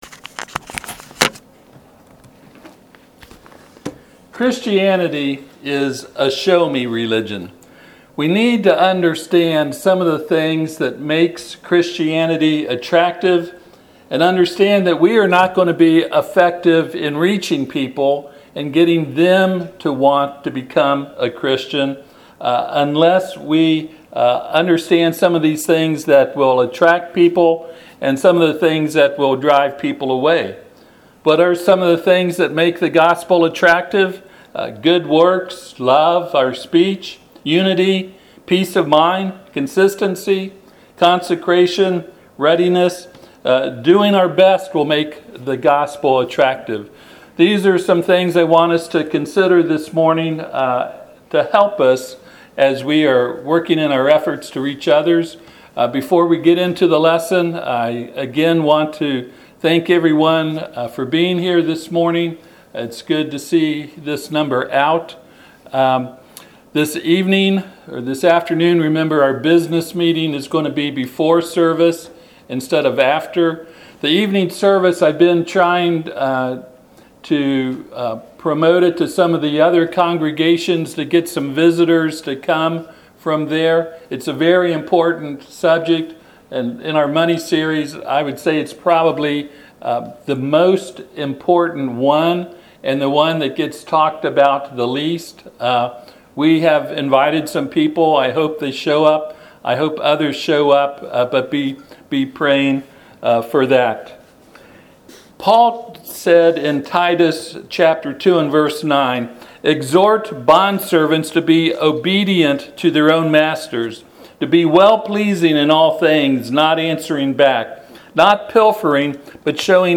Titus 2:9-10 Service Type: Sunday AM Christianity is a “Show Me” religion.